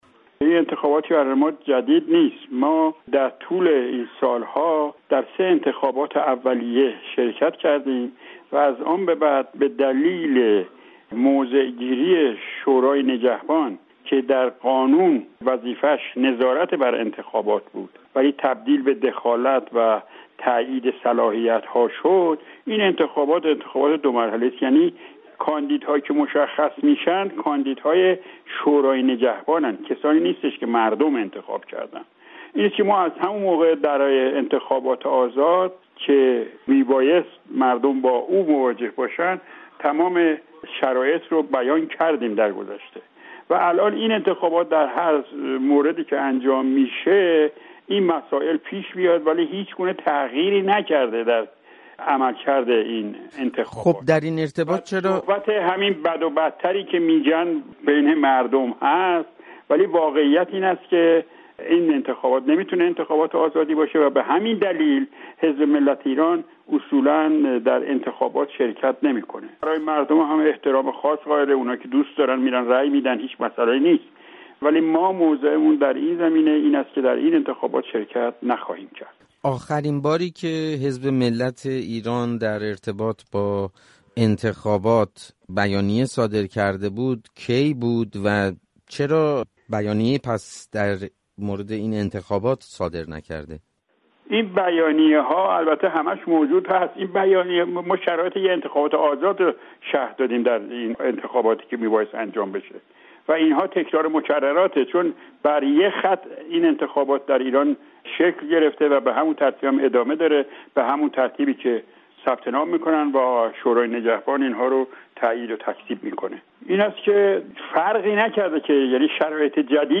گفتگوی رادیو فردا